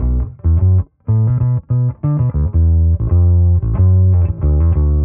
Index of /musicradar/dusty-funk-samples/Bass/95bpm
DF_PegBass_95-B.wav